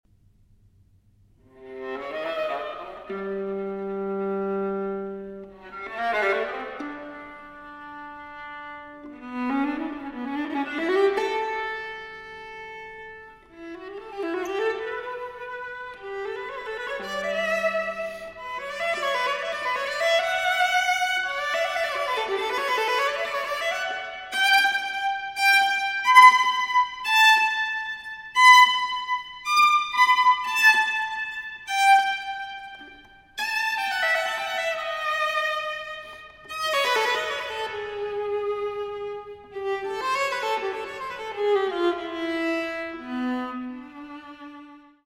for Viola